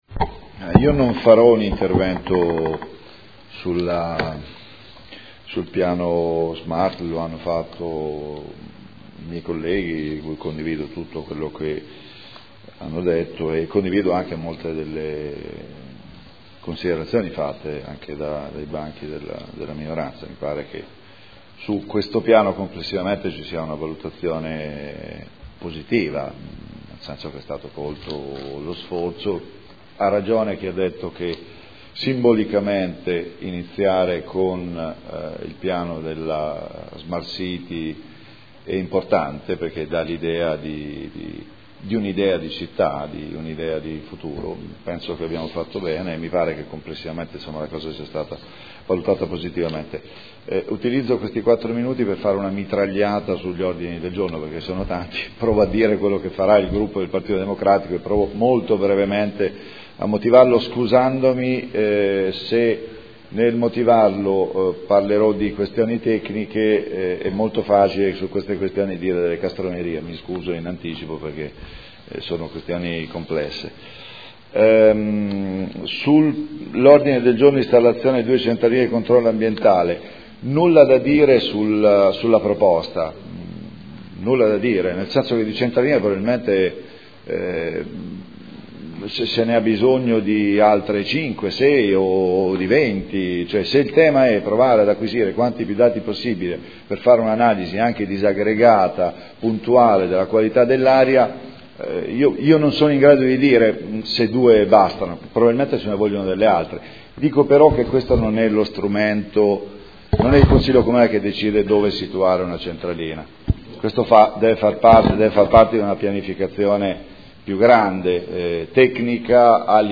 Seduta del 18 settembre. “PROGRAMMA SMART CITY” - Presentazione vari odg e Dibattito